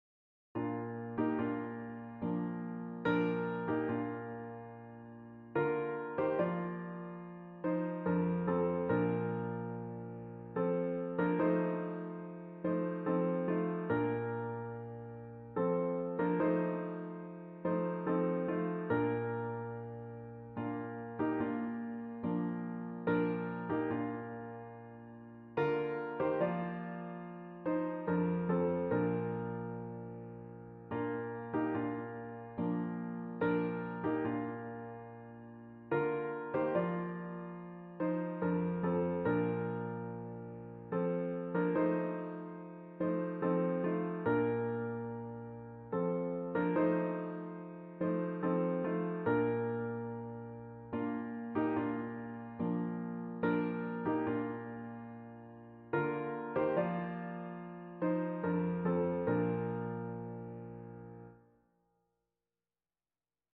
A traditional hymn
for piano